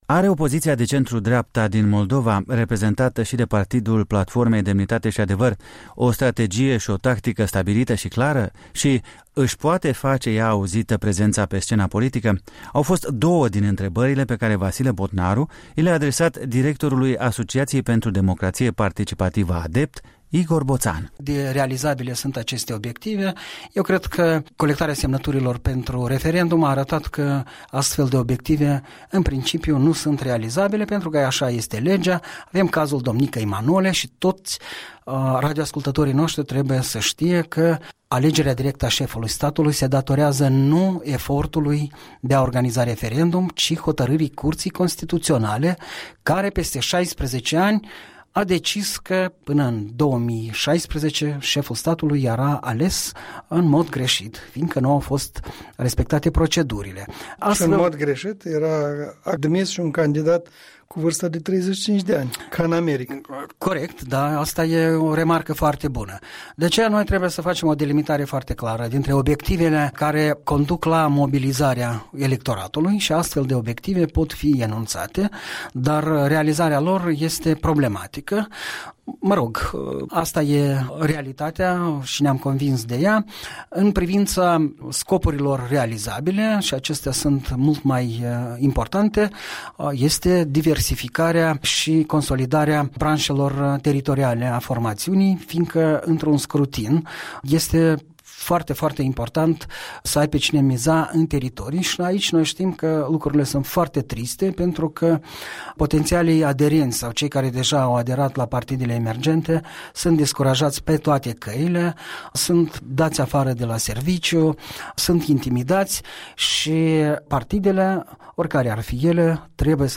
Un punct de vedere săptămînal în dialog.